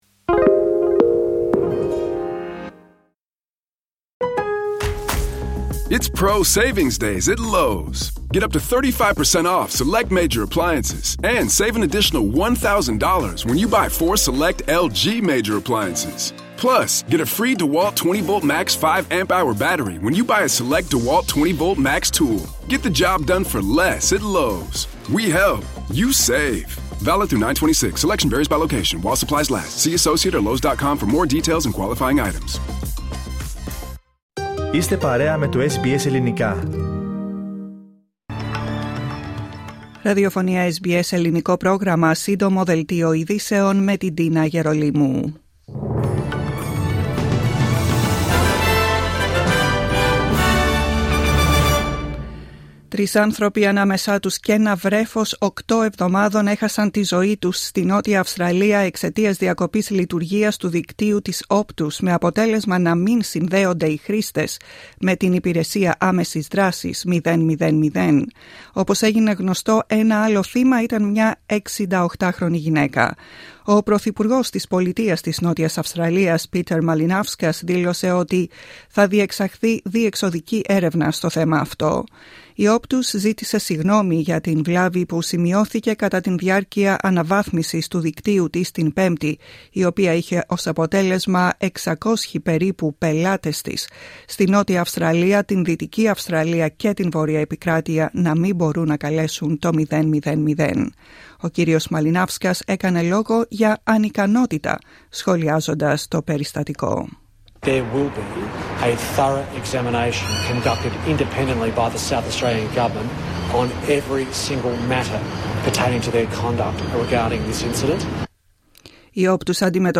Σύντομο δελτίο ειδήσεων απ΄το Ελληνικό Πρόγραμμα της SBS.